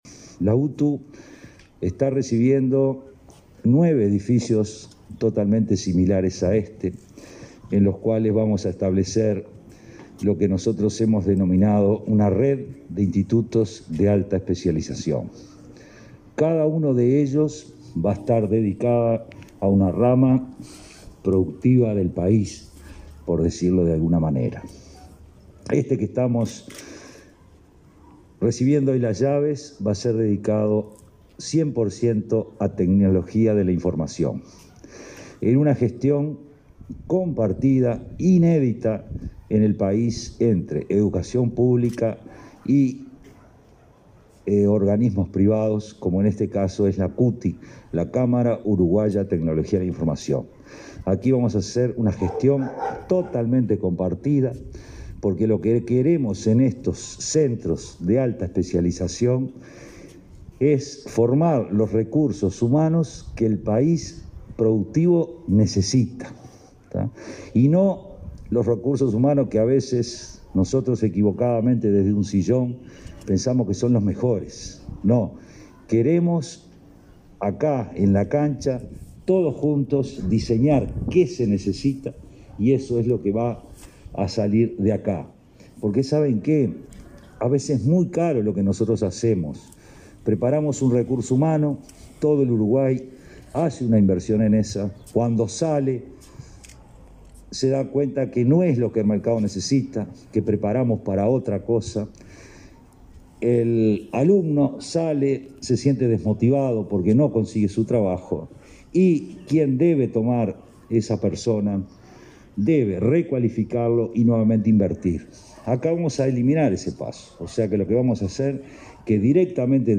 Declaraciones del director general de la UTU, Juan Pereyra
La ANEP inauguró obras en Montevideo y Canelones este 20 de agosto. El titular de la UTU participó del evento.